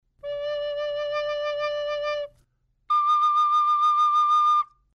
A top tone and a bottom tone, so to speak.
Nature's Interval - Recorder
recorder_d_octave.mp3